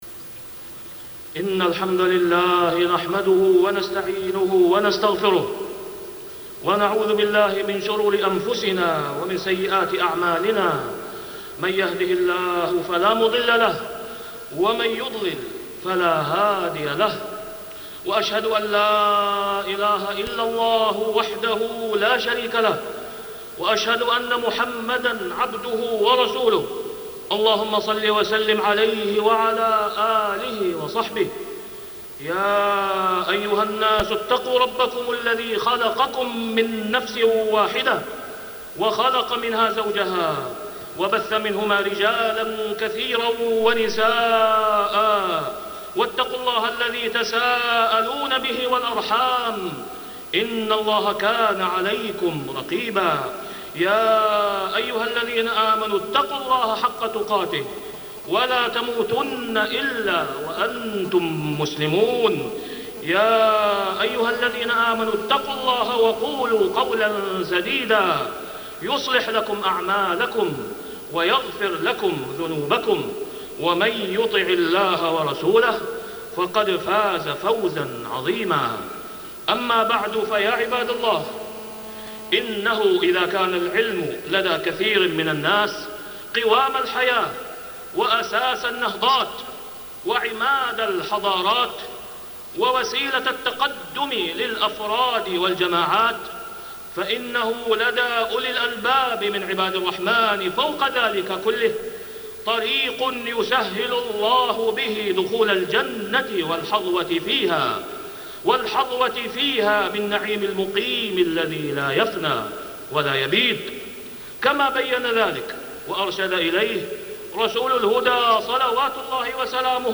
تاريخ النشر ٥ شعبان ١٤٢٦ هـ المكان: المسجد الحرام الشيخ: فضيلة الشيخ د. أسامة بن عبدالله خياط فضيلة الشيخ د. أسامة بن عبدالله خياط فضل العلم